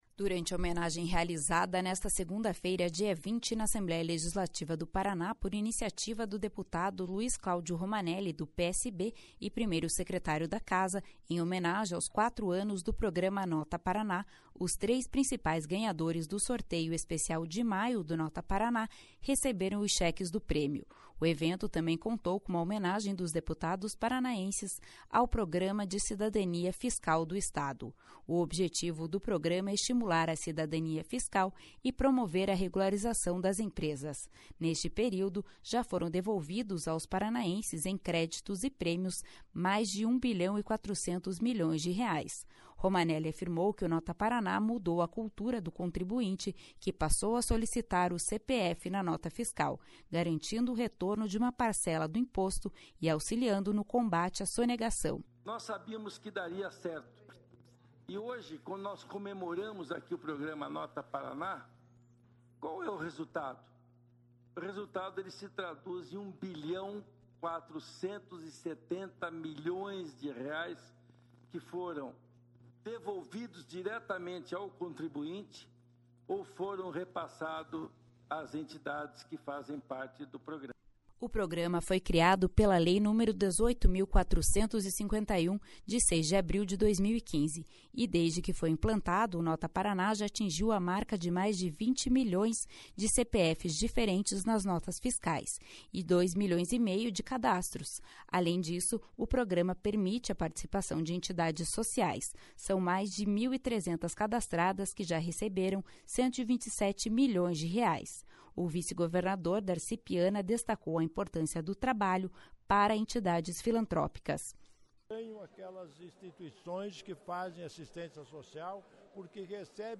O vice-governador Darci Piana destacou a importância do trabalho para entidades filantrópicas.